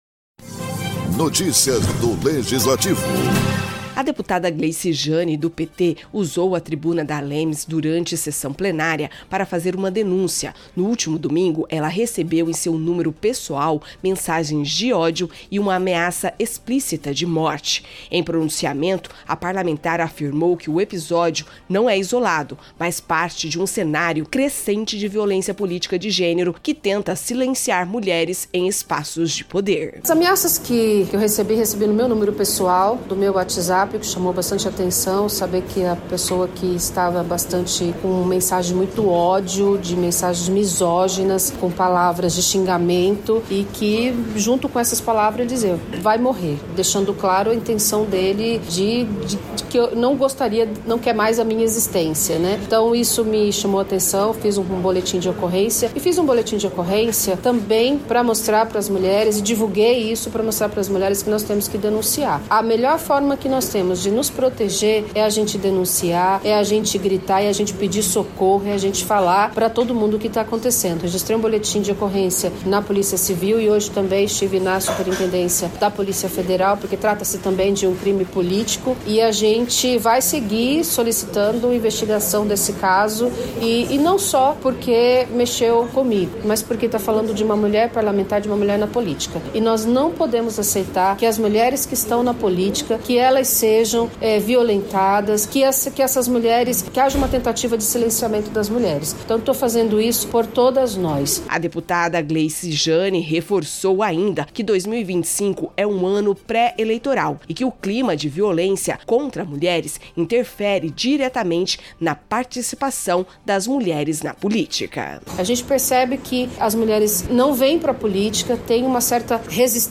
A deputada estadual Gleice Jane (PT) denunciou, durante a sessão plenária da ALEMS, ter recebido uma ameaça de morte em seu WhatsApp pessoal. Ela afirmou que o caso integra um cenário crescente de violência política contra mulheres. O Parlamento se manifestou em solidariedade e reforçou o compromisso com o enfrentamento à violência de gênero.